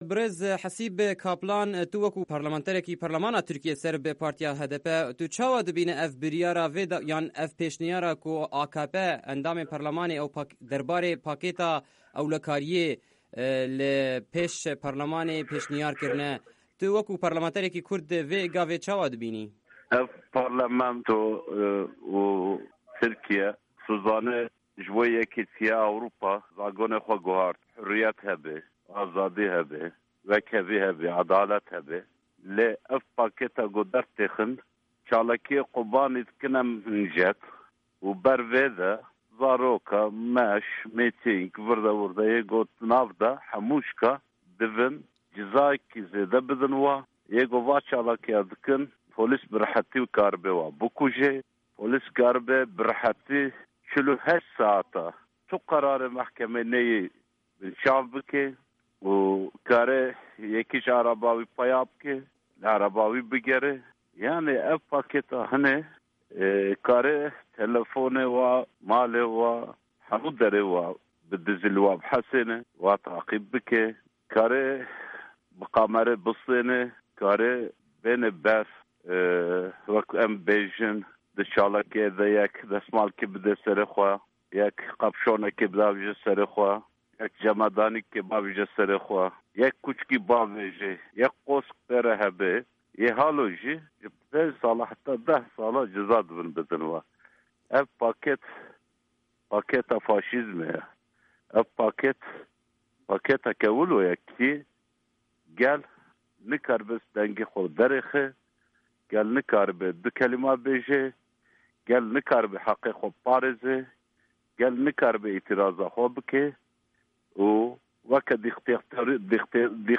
Hevpeyvîn bi Hasîp Kaplan re